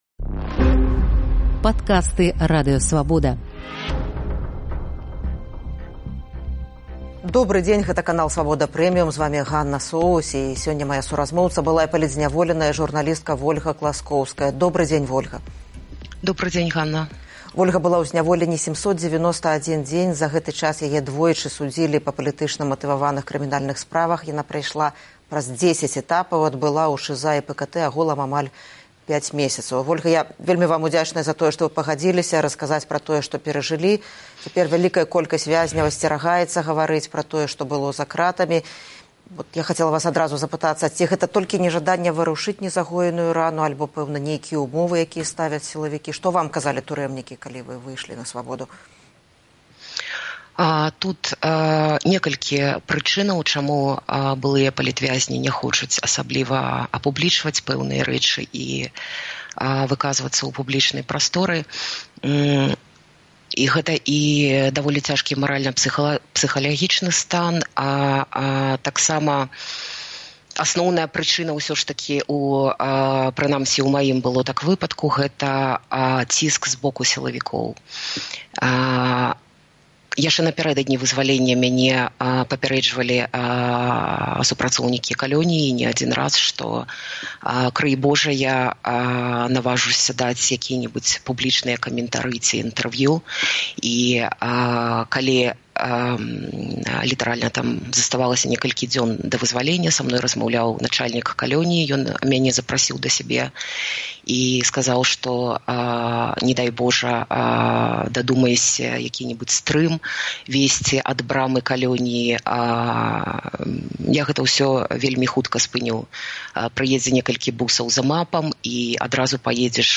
Першае відэаінтэрвію